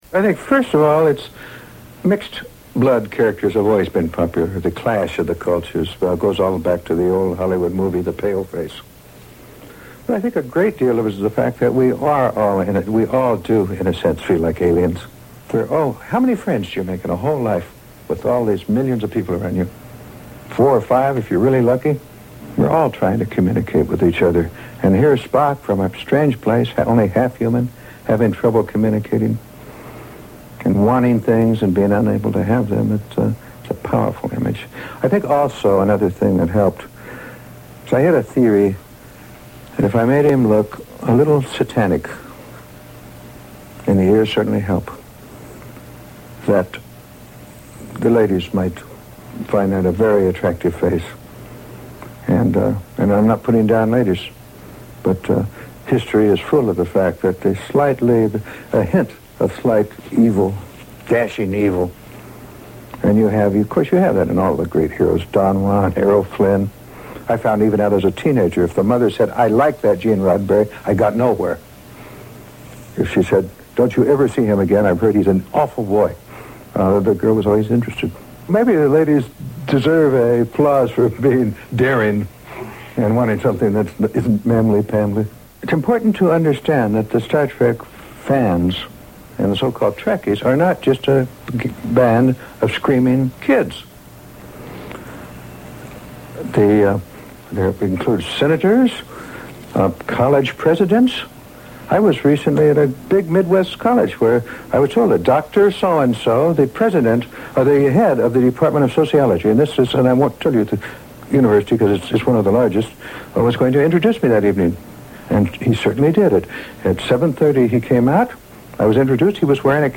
Actor Leonard Nimoy will forever be remembered as the logical pointy-eared Vulcan first officer Spock. In these clips from the Radio 2 movie programme Star Sound Extra broadcast in 1984 Nimoy and Star Trek creator Gene Roddenberry talk about the genesis of Spock.